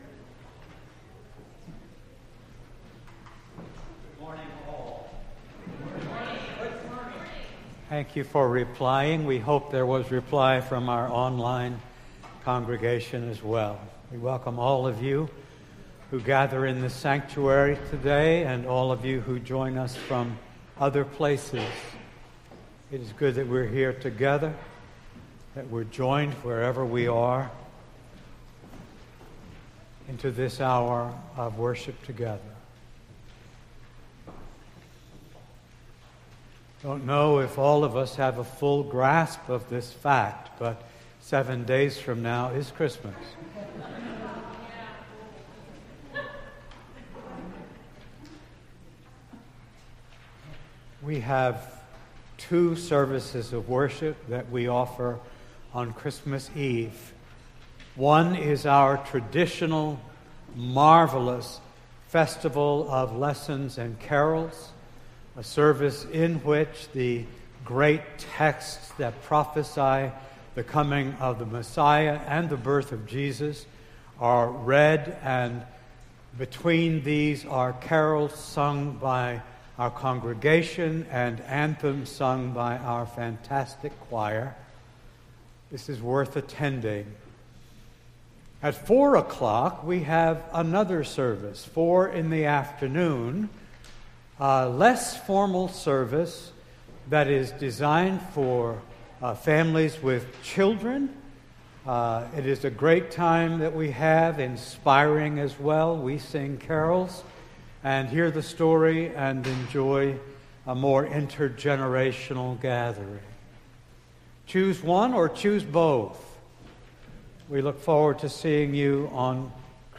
Entire December 18th Service